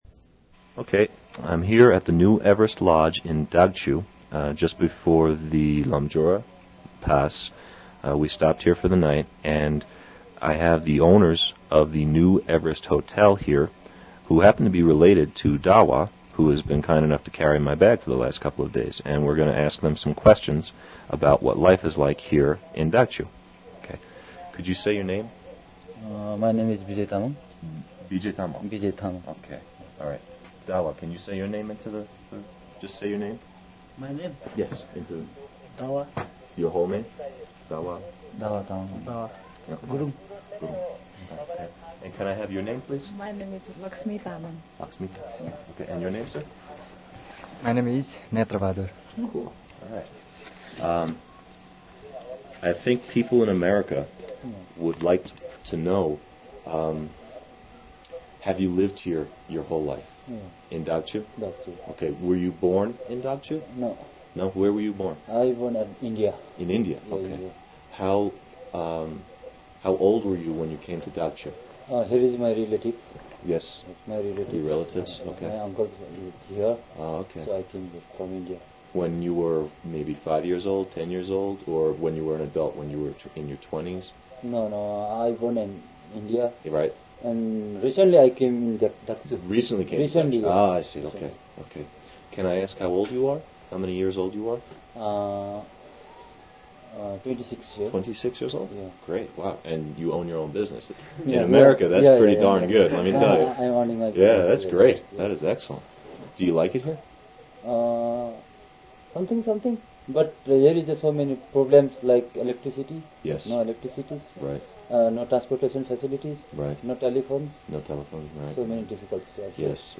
Interview with lodge owners in Dagchu- part 1